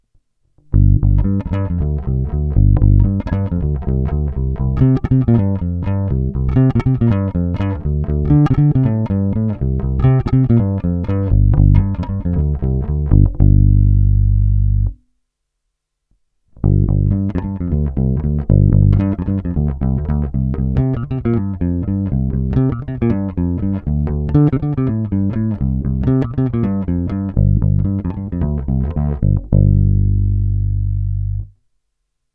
Zvuk je s těmito strunami opravdu hodně vintage a svádí ke dvěma herním stylům.
Není-li uvedeno jinak, tak nahrávky jako vždy rovnou do zvukovky, s plně otevřenou tónovou clonou a jen normalizovány.